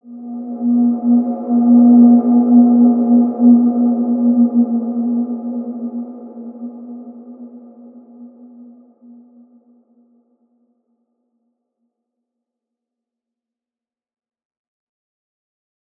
Large-Space-B3-mf.wav